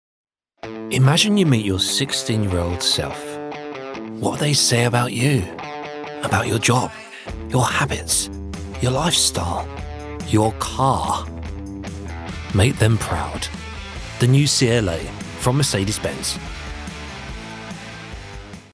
Automobile
Mon accent est celui de l'estuaire, mais la plupart me connaissent comme un gars de l'Essex.
Baryton